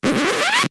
sfx record SCRATCH
Category: Sound FX   Right: Personal